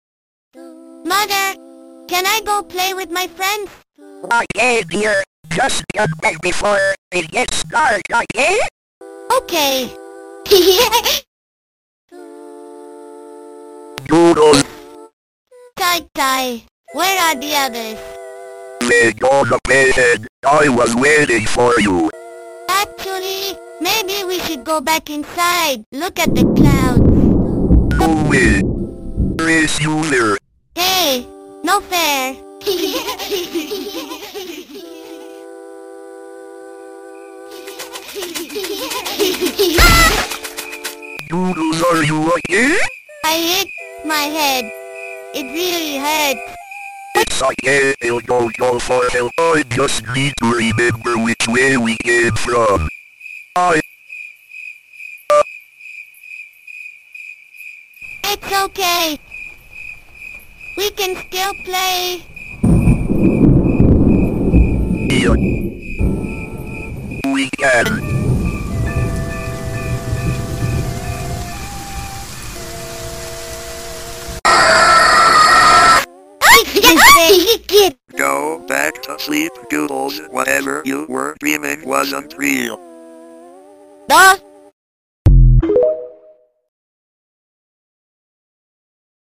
But SAM (Software Automatic Mouth) voiced mother and Ty-Ty.